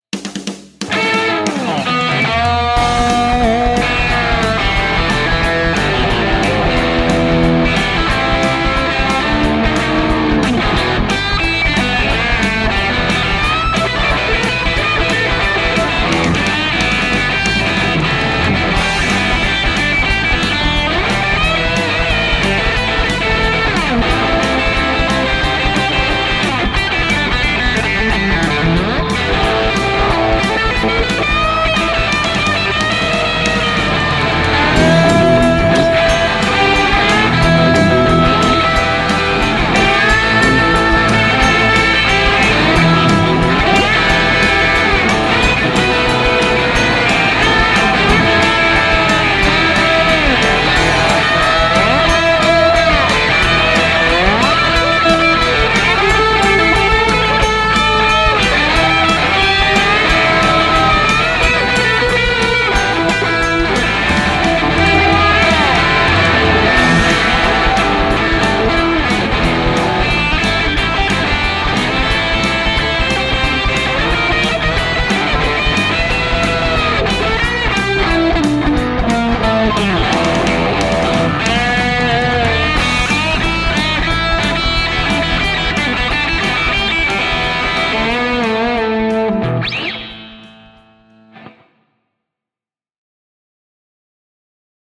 - soita soolosi annetun taustan päälle
- taustan tulee olla sointukierroltaan blues (I-IV-V)- sointuja ja tyyliä soveltaen.
Alkupuolella hyvää möyryävää meininkiä. Loppu menee tuplakitaroilla aika villiksi.
Voimakasta tarinointia taustan hengessä.